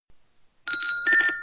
jetson-phone_25375.mp3